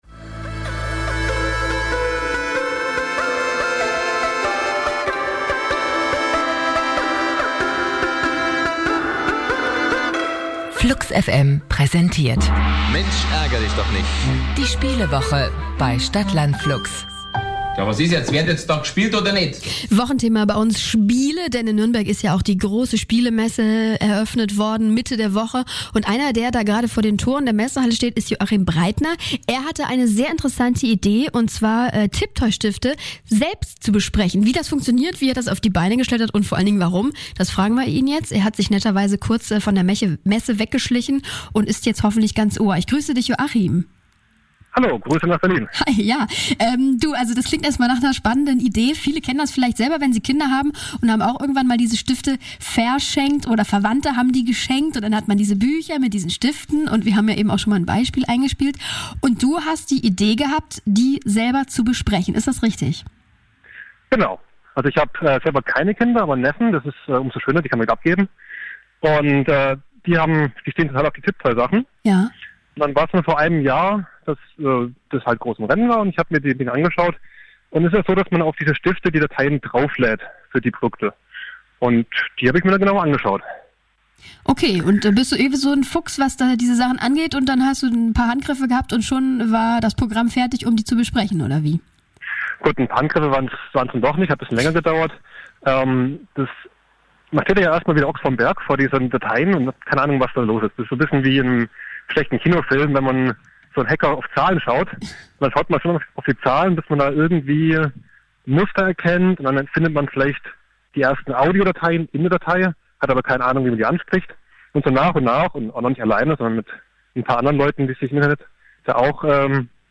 Tiptoi-Basteleien live im Radio
Während dem Gespräch war ich gerade vor den Toren der Spielwarenmesse in Nürnberg, zu der ich von Ravensburger eingeladen wurde, die inzwischen auf das Projekt aufmerksam geworden sind und mich kennen lernen wollten.